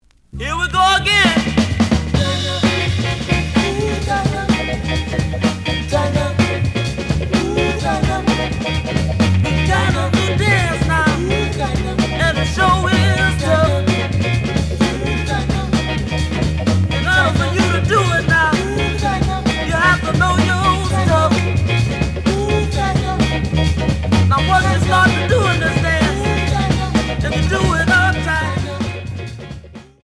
The audio sample is recorded from the actual item.
●Genre: Funk, 60's Funk
Slight edge warp.